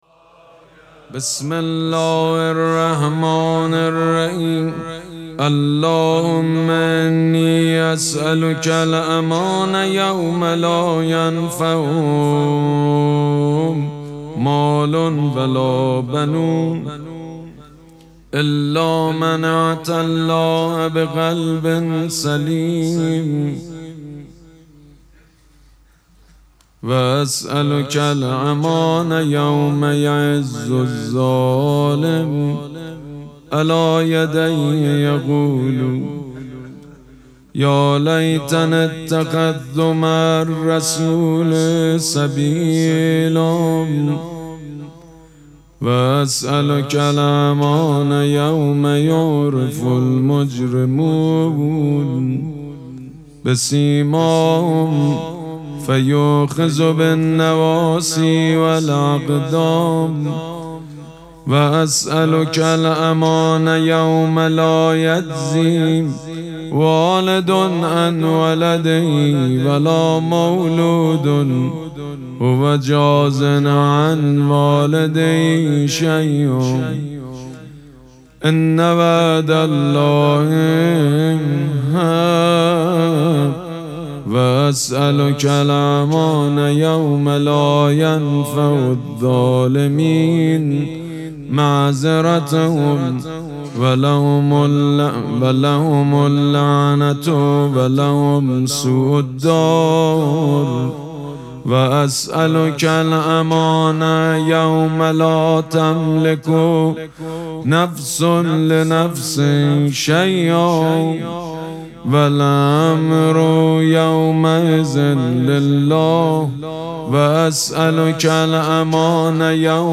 مراسم مناجات شب هشتم ماه مبارک رمضان شنبه ۱۸ اسفند ماه ۱۴۰۳ | ۷ رمضان ۱۴۴۶ حسینیه ریحانه الحسین سلام الله علیها